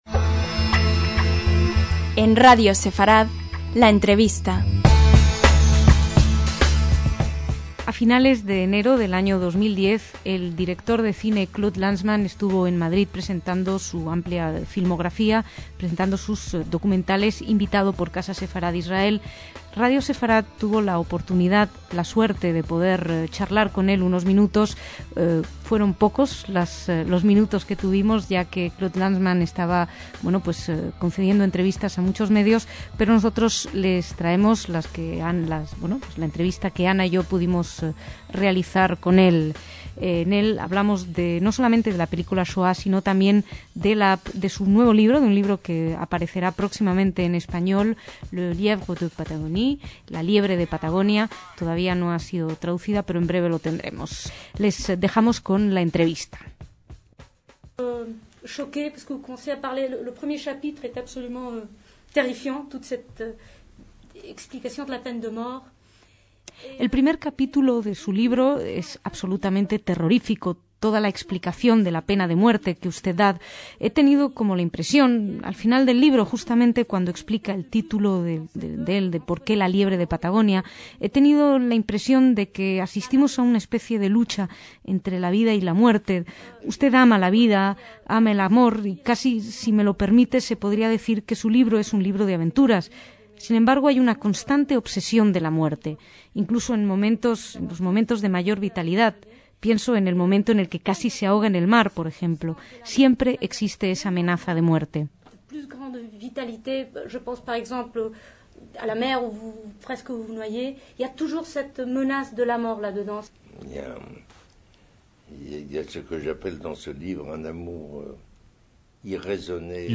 LA ENTREVISTA - En febrero de 2010, el cineasta francés Claude Lanzmann (1925 - 2018) visitó Madrid invitado por el Centro Sefarad Israel.